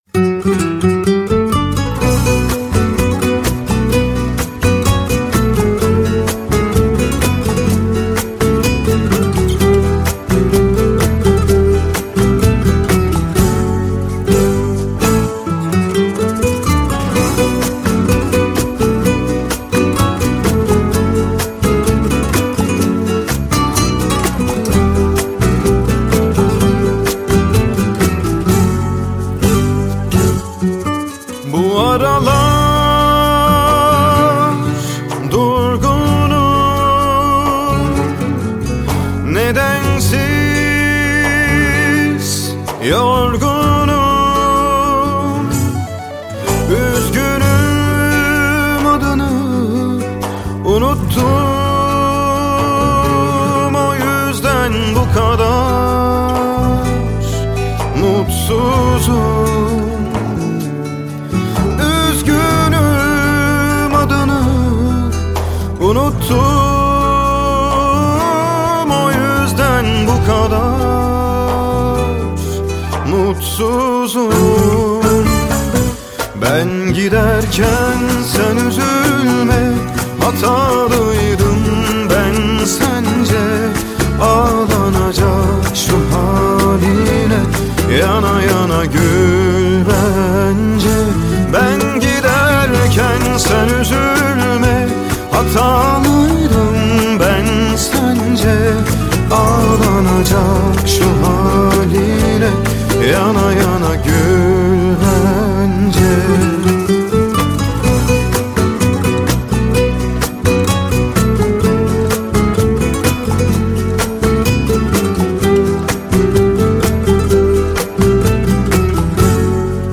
نظر خودتان را درباره این آهنگ شاد ترکی برای ما بنویسید.